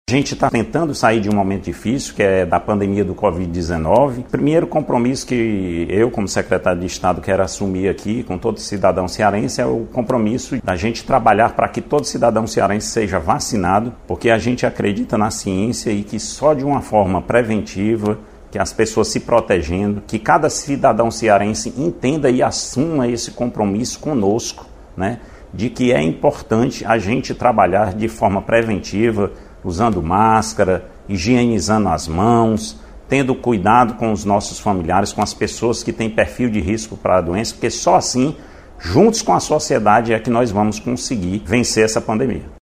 O secretário da Saúde, Dr. Marcos Gadelha, reafirma o compromisso de vacinar todos os cearenses, medida essencial para combater o avanço da doença no Estado.